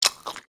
bite-small3.wav